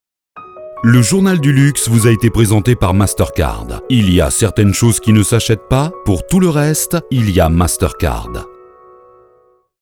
Pymprod réalise pour vous des spots publicitaires avec nos voix off masculines et féminines.